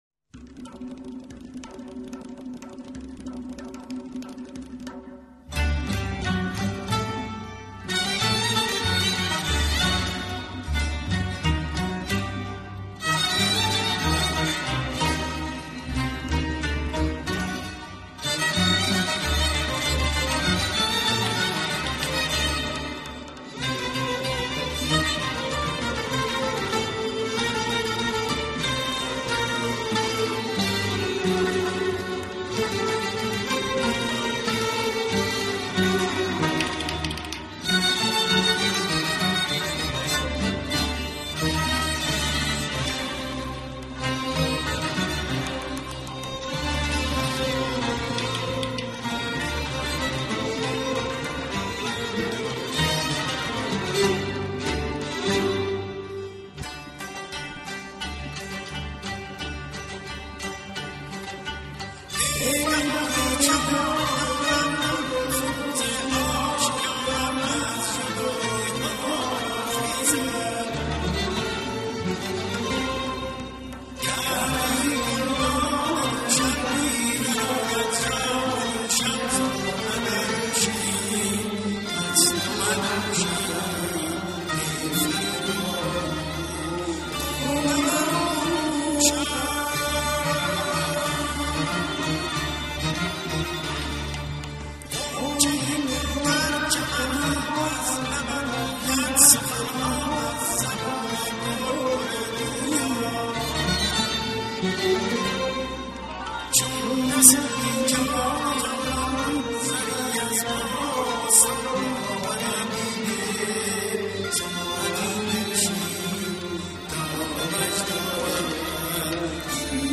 خوانندهٔ موسیقی اصیل ایرانی است .